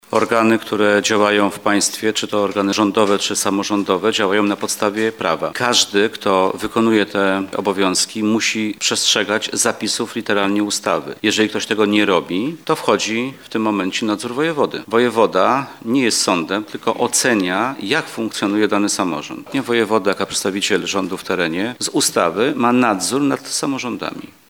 – Do września urzędnicy stołecznego ratusza mieli czas na wprowadzenie własnych zmian. Tego nie zrobili, dlatego to Urząd Wojewódzki musiał się tym zająć – tłumaczy Wojewoda Mazowiecki, Zdzisław Sipiera.